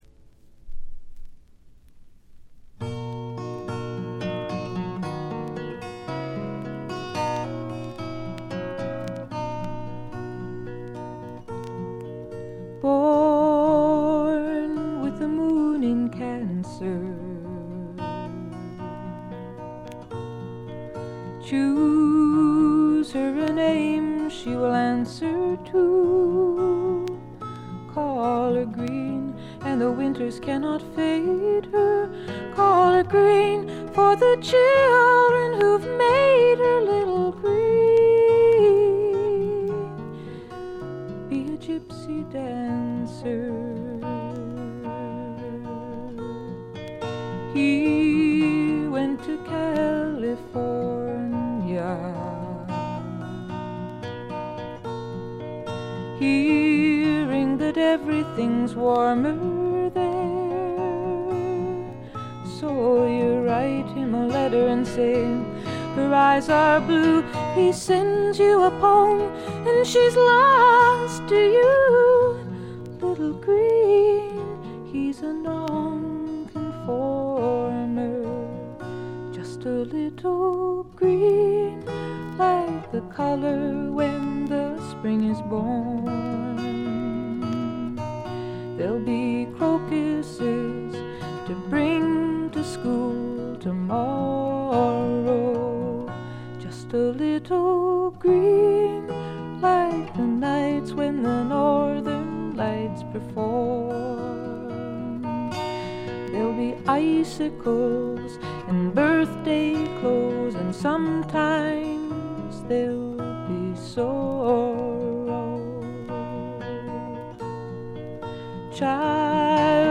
全体にバックグラウンドノイズ。細かなチリプチ多めですが、鑑賞を妨げるほどのノイズはないと思います。
ほとんど弾き語りに近いごくシンプルな演奏が染みます。
試聴曲は現品からの取り込み音源です。